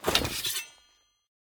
draw1.ogg